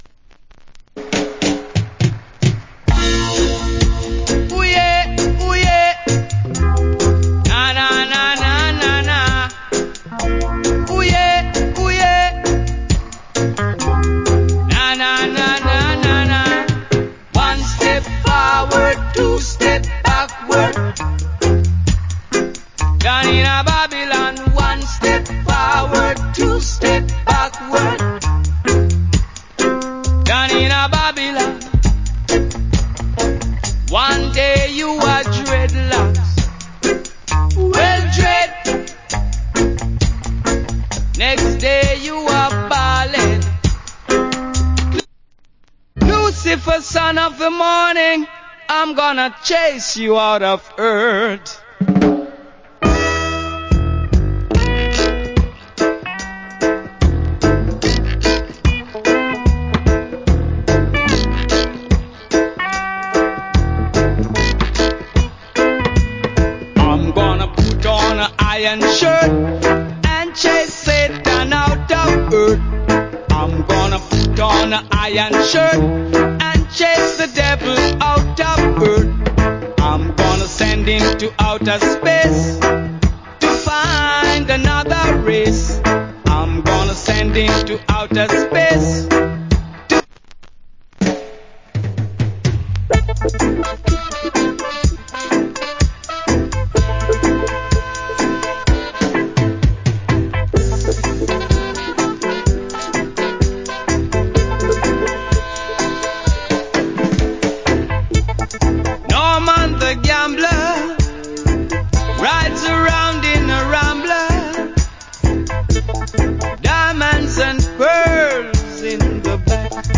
Great Roots